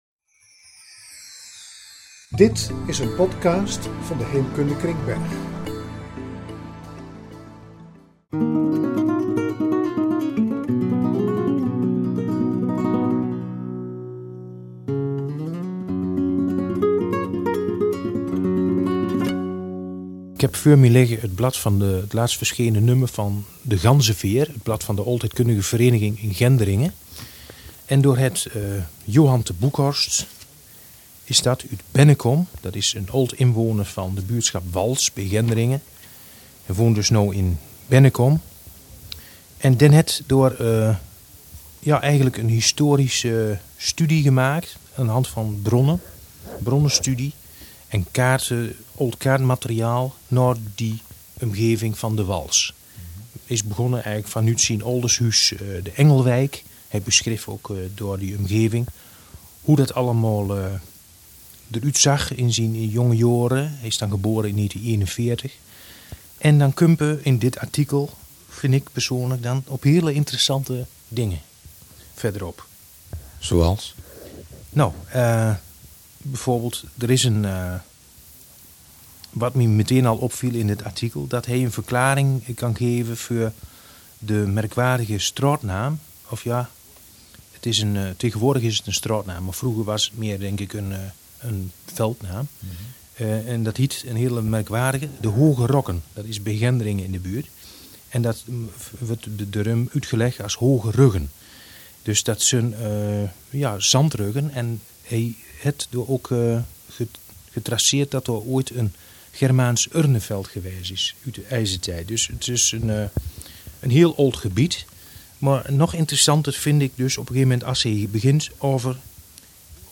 Podcast met geluidsopnames